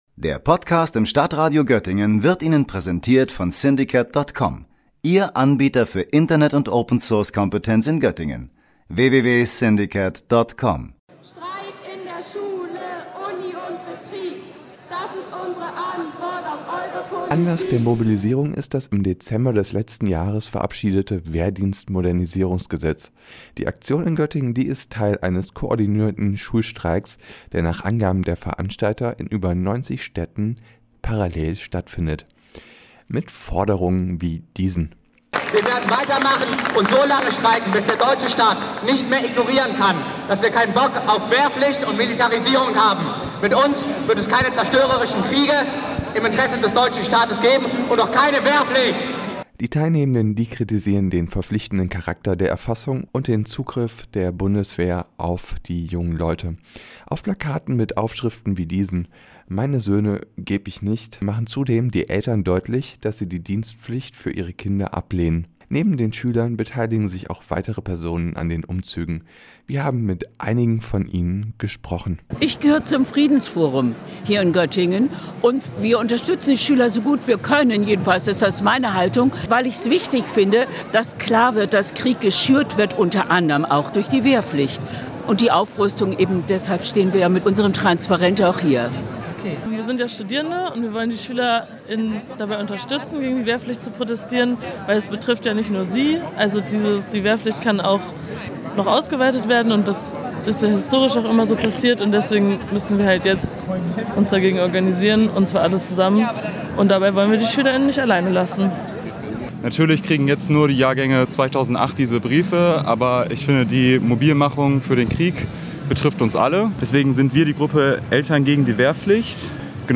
In mehreren Protestzügen demonstrierten heute Schüler und weitere Personen gegen die Wiedereinführung der Wehrpflicht. Ab dem diesem Jahr werden Fragebögen an junge Menschen versendet, damit diese sich mit der Wehrpflicht auseinandersetzen. Bei der Demonstration
mit den Teilnehmern gesprochen